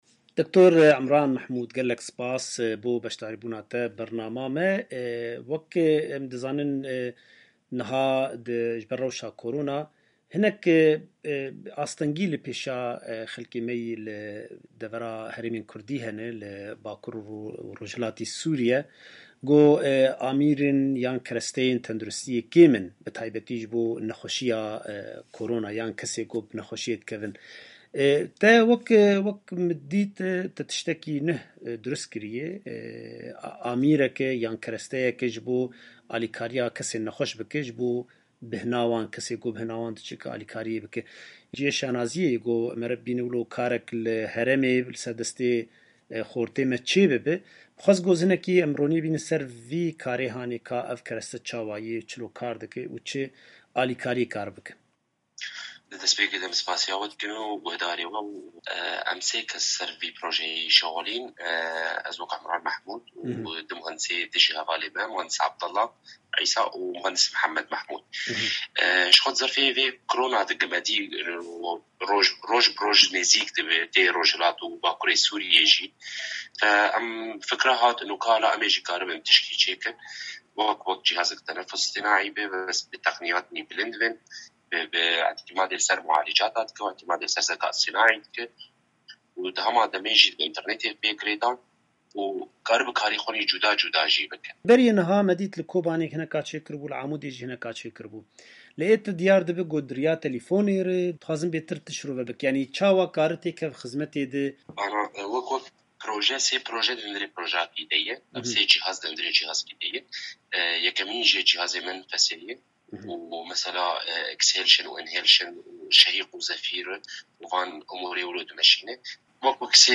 Dengê Amerîka hevpyvînek